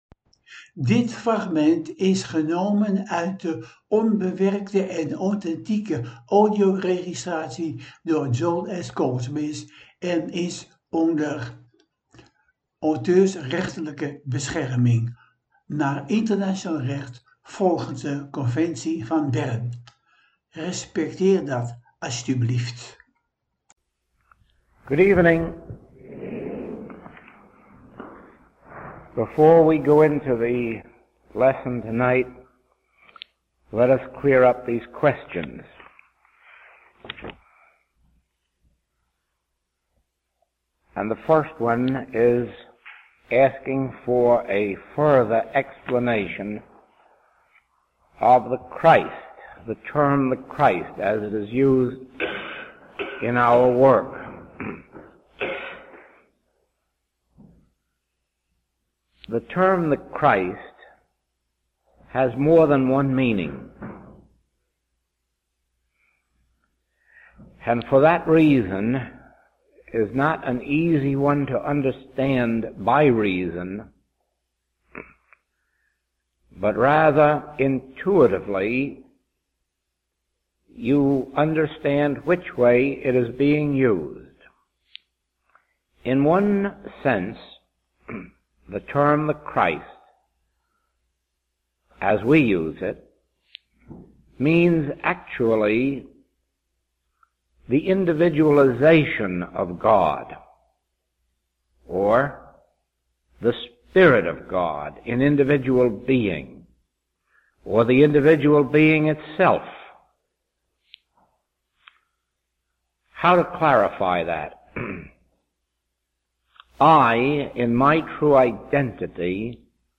ENKELE FRAGMENTEN UIT DE AUTHENTIEKE, ONBEWERKTE AUDIOREGISTRATIES